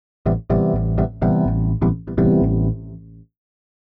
ORGAN025_VOCAL_125_A_SC3(L).wav
1 channel